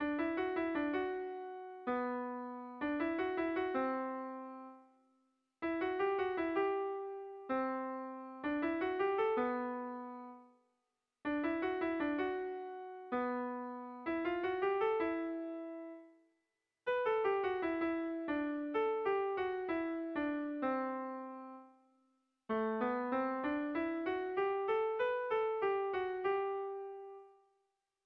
Hamarreko txikia (hg) / Bost puntuko txikia (ip)
ABA2DE